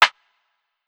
{Snare} Stud.wav